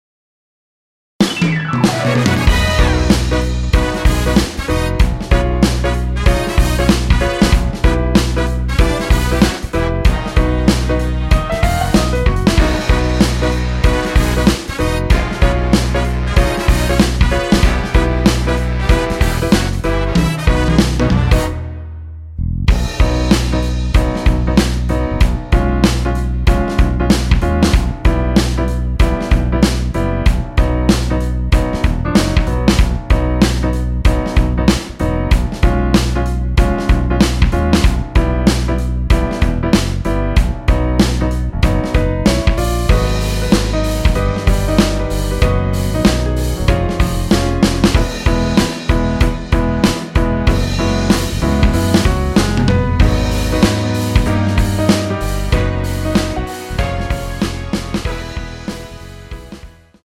원키에서(-3)내린 MR입니다.
앞부분30초, 뒷부분30초씩 편집해서 올려 드리고 있습니다.
중간에 음이 끈어지고 다시 나오는 이유는
축가 MR